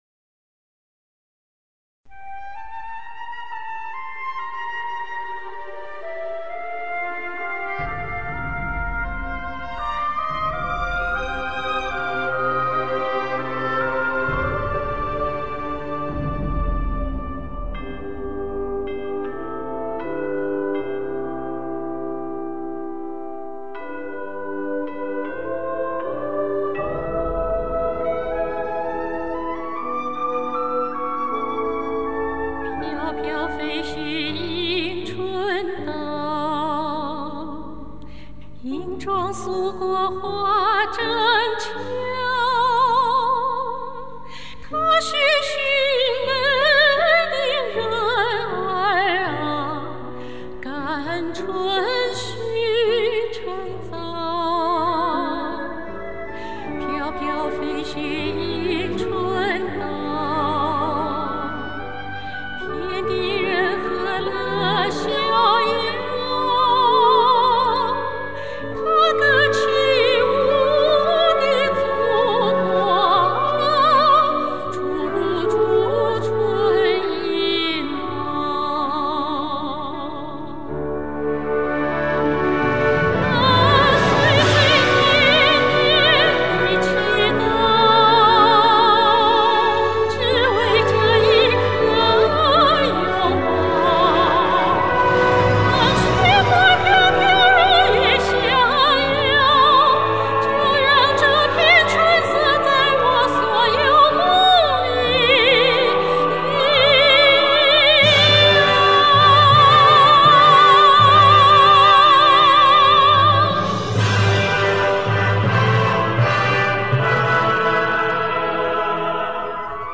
很久没唱歌了，嗓子有点儿锈，将就着听了，对不起哈。